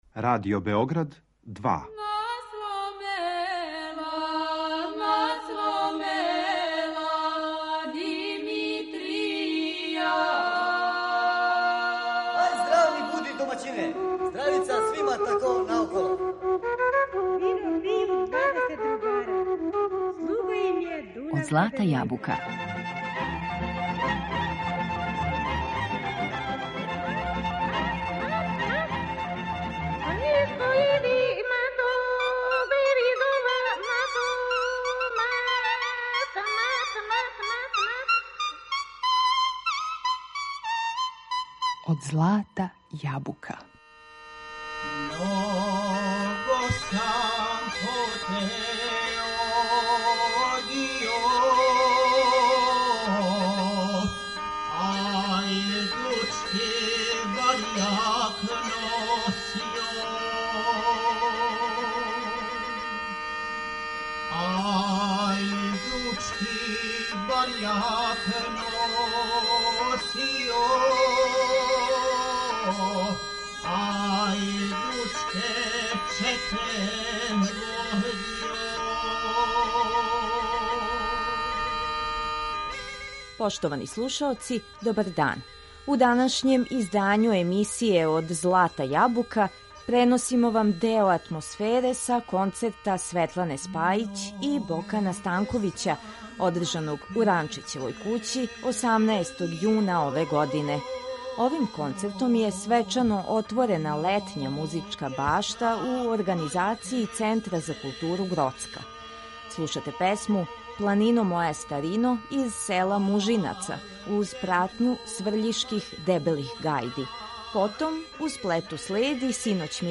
Наши признати уметници традиционалне музике отворили су овогодишњу концертну сезону у Ранчићевој кући, 18. јуна, у организацији Центра за културу Гроцка.